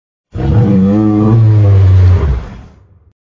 Рычание одинокого яка